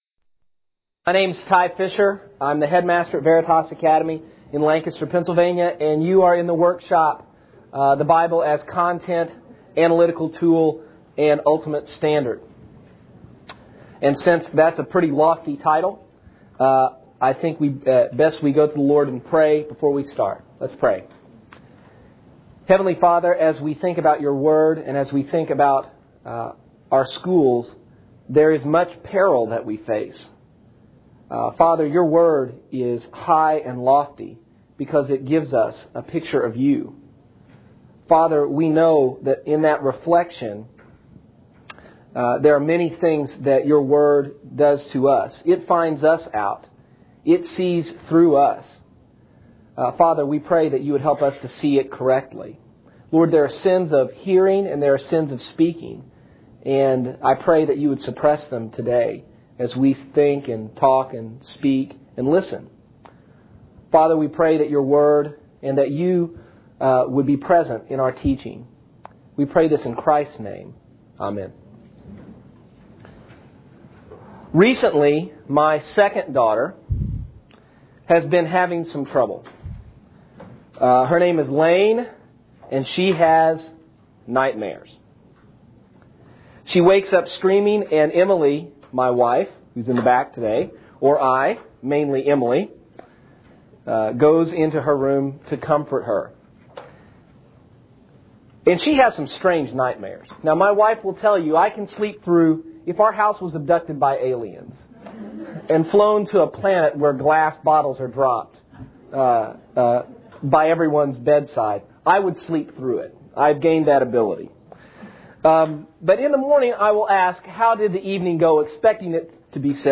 2004 Workshop Talk | 0:59:54 | All Grade Levels, General Classroom, Theology & Bible
Additional Materials The Association of Classical & Christian Schools presents Repairing the Ruins, the ACCS annual conference, copyright ACCS.